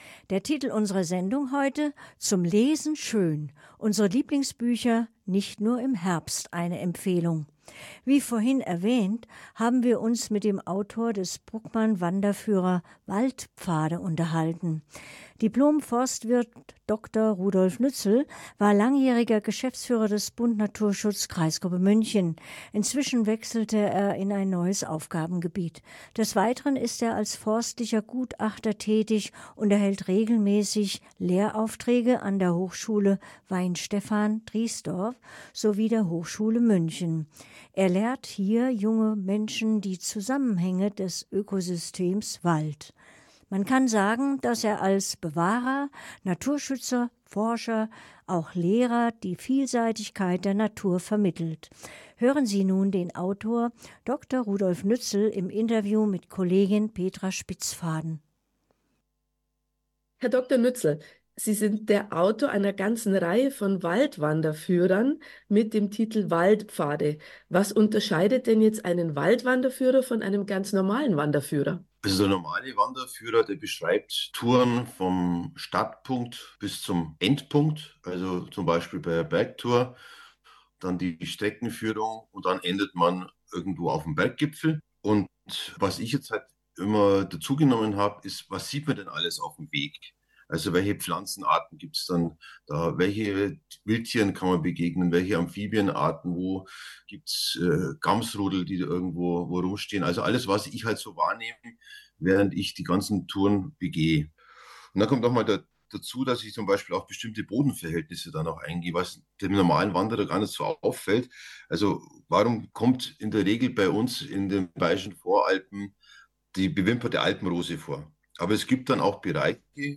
Interview 1